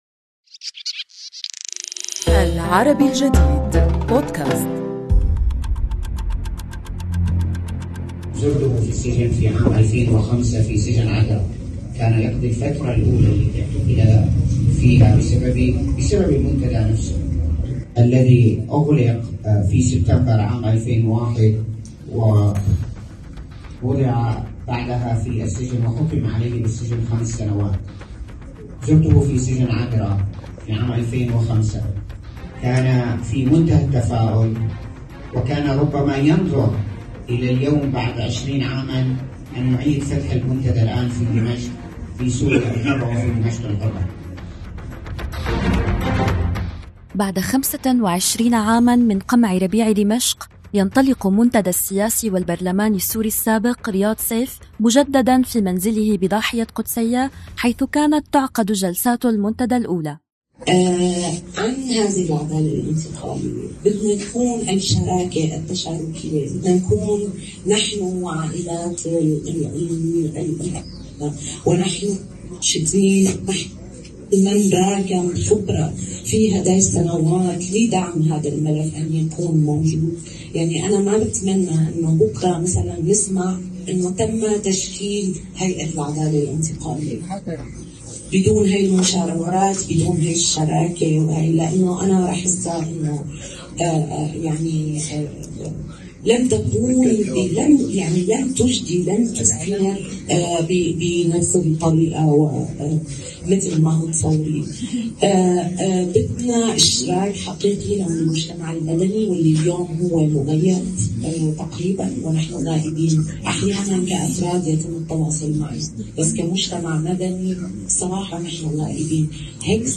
كان لنا هذا اللقاء